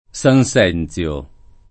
San Senzio [ S an S$ n ZL o ]